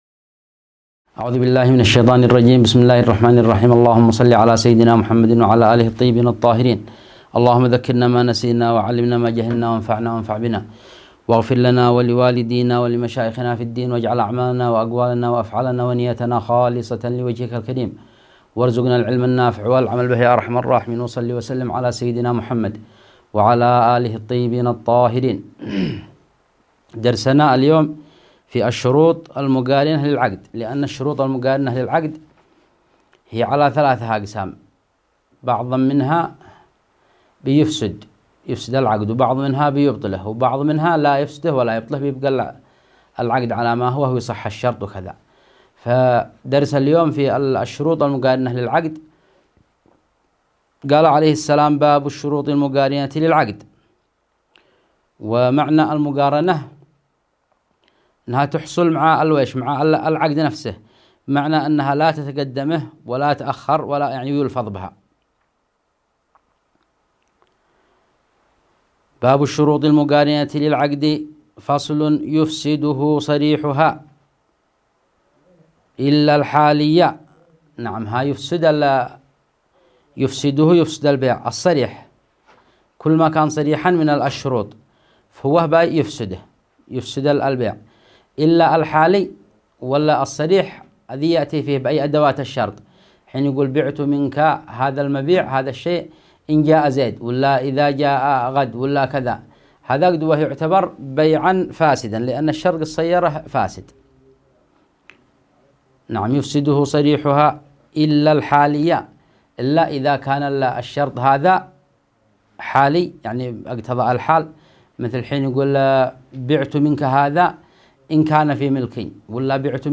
الدرس12-فصل في الشروط التي تبطل العقد